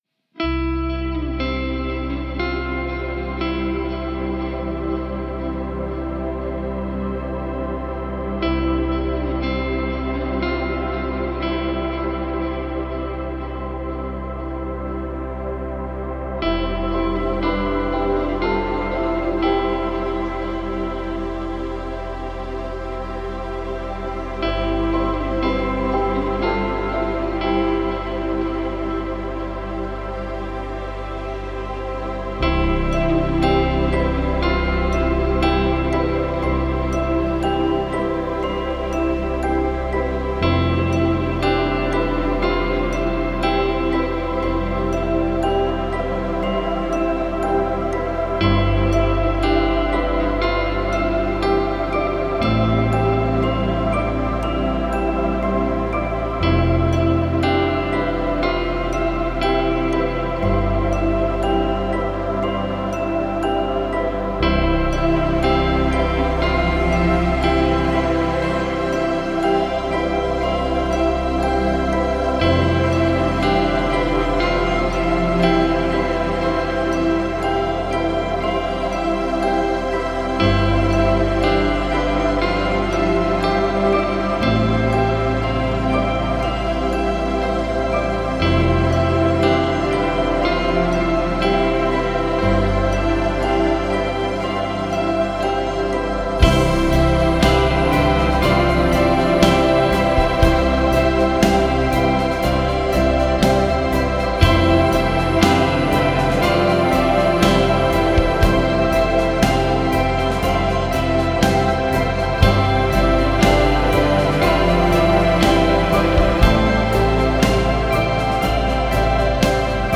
Ambient موسیقی بیکلام Instrumental